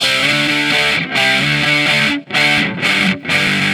Guitar Licks 130BPM (19).wav